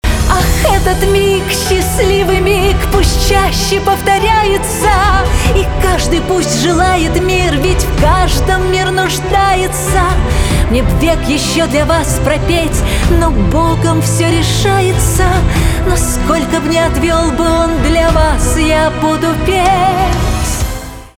эстрада
чувственные
скрипка , пианино , барабаны , грустные